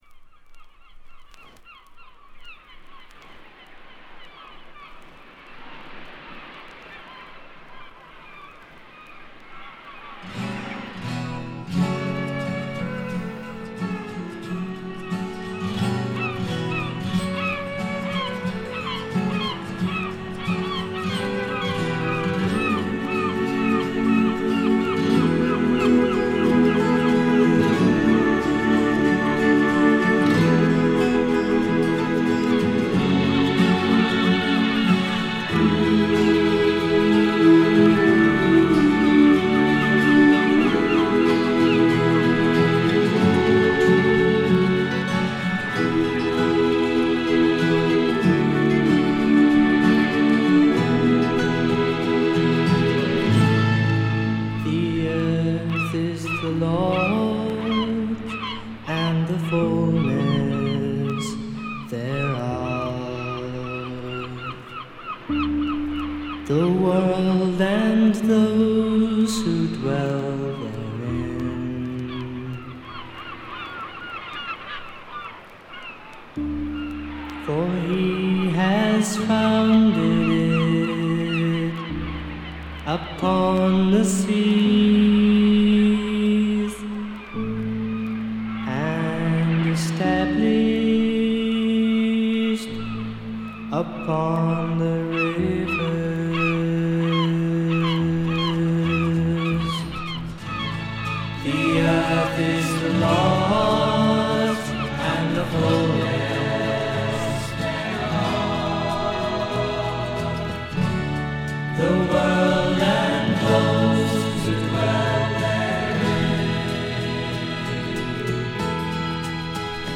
ところどころで軽微なチリプチ。
妖精フィメール入り英国ミスティック・フォーク、ドリーミー・フォークの傑作です。
霧深い深山幽谷から静かに流れてくるような神秘的な歌の数々。
メロトロン入りということでも有名。
それにしても録音の悪さが幸いしてるのか（？）、この神秘感は半端ないです。
試聴曲は現品からの取り込み音源です。
Mellotron